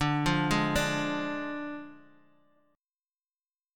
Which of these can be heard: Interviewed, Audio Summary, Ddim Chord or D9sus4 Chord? Ddim Chord